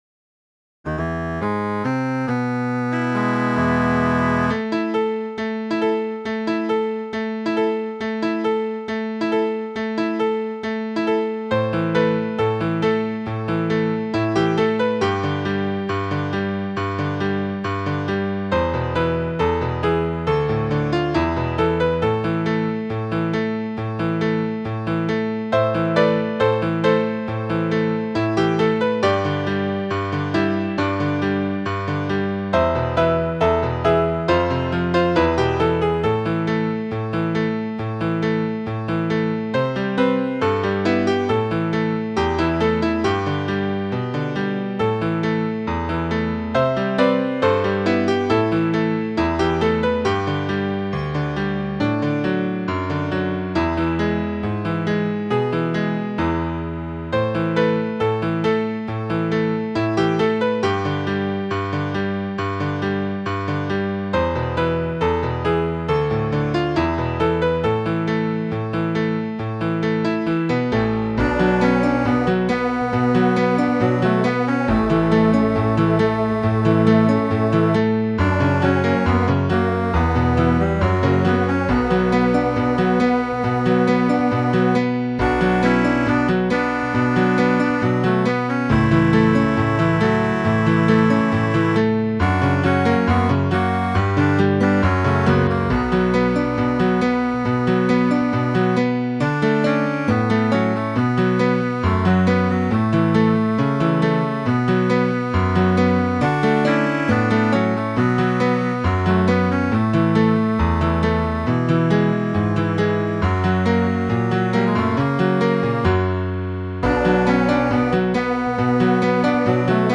Wistful piece.
SAD MUSIC